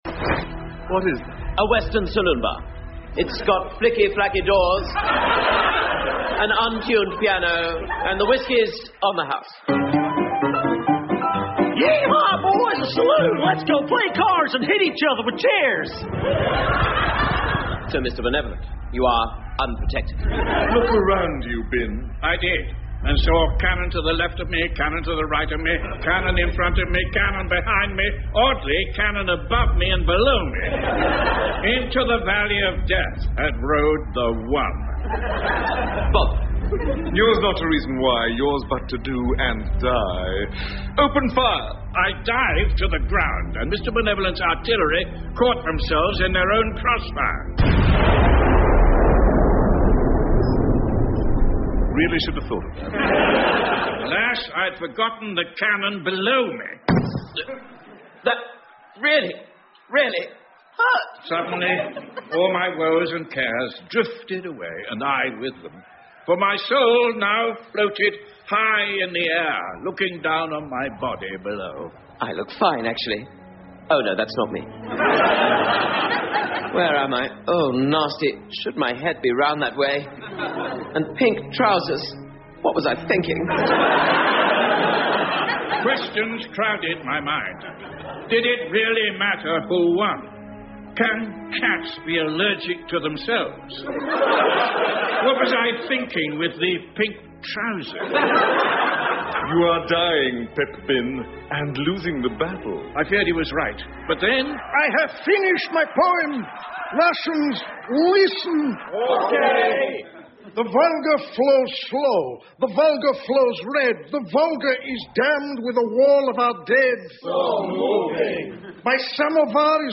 英文广播剧在线听 Bleak Expectations 119 听力文件下载—在线英语听力室